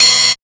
175FT4RIDE-L.wav